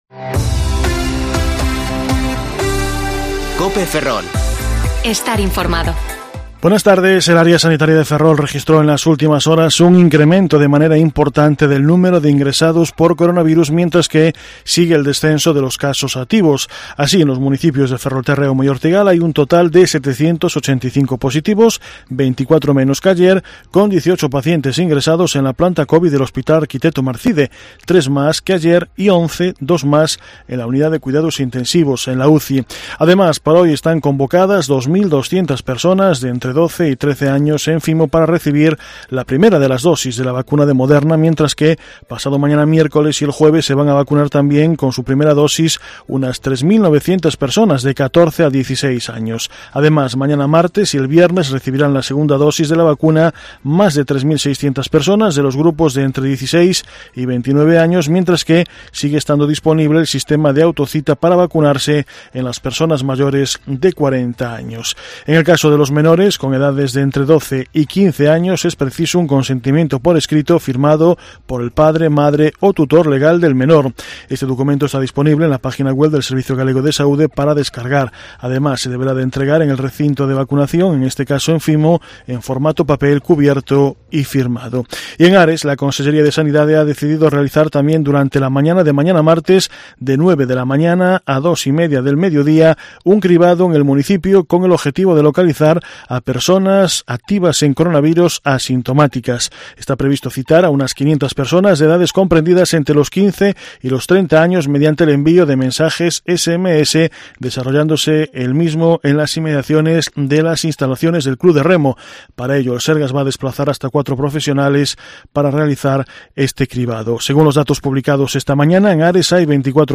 Informativo Mediodía COPE Ferrol 23/8/2021 (De 14,20 a 14,30 horas)